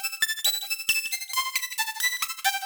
Index of /musicradar/shimmer-and-sparkle-samples/90bpm
SaS_Arp03_90-A.wav